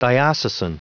Vous êtes ici : Cours d'anglais > Outils | Audio/Vidéo > Lire un mot à haute voix > Lire le mot diocesan
Prononciation du mot : diocesan